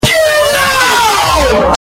Get Out Slowed